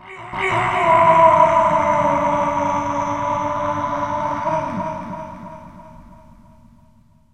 PixelPerfectionCE/assets/minecraft/sounds/mob/wither/idle1.ogg at mc116